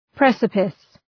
Προφορά
{‘presəpıs} (Ουσιαστικό) ● κρημνός ● γκρεμός